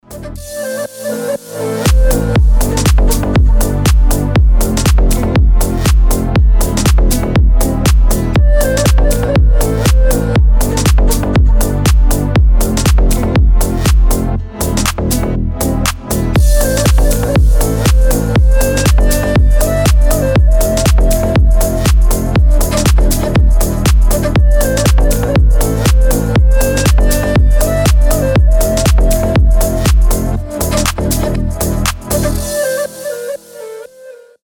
• Качество: 320, Stereo
deep house
мелодичные
без слов
восточные
Красивая музыка востока